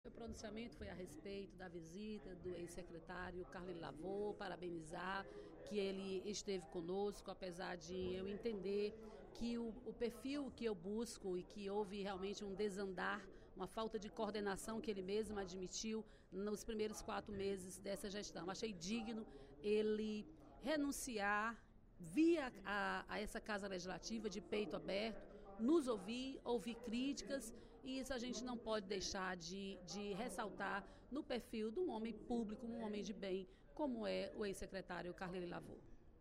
Durante o primeiro expediente da sessão plenária desta quinta-feira (21/05), a deputada Dra. Silvana (PMDB) comentou a situação da saúde no Ceará e a gestão da Secretaria da Saúde do Ceará (Sesa).